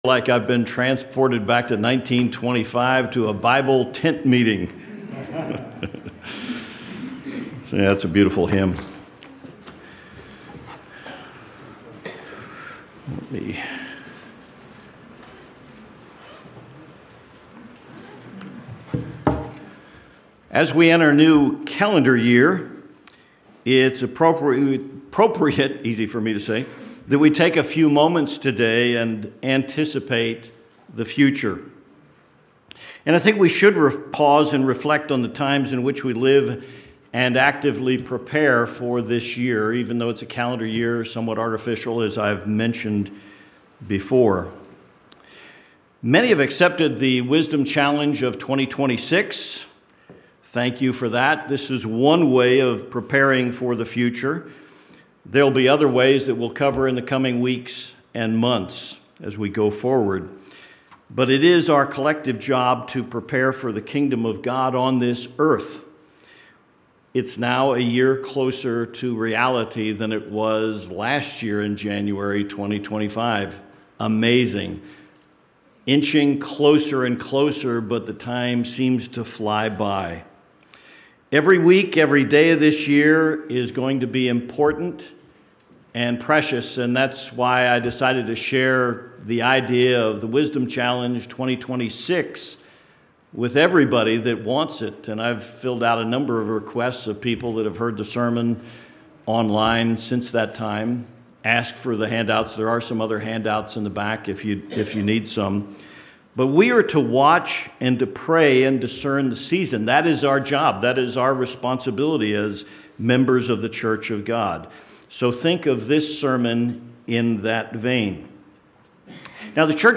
What might be some of the prophetic themes of 2026 and beyond? This sermon explores the possibility that a renewed interest in Christ's return in the next few years as we approach the 2,000 anniversary of His public ministry.